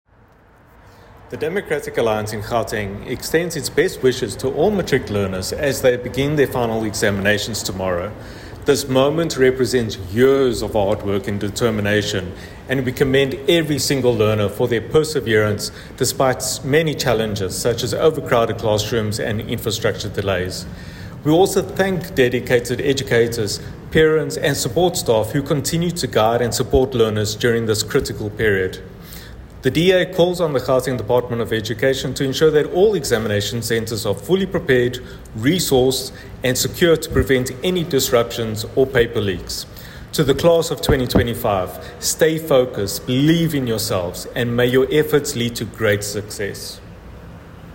soundbite by Sergio Isa Dos Santos MPL.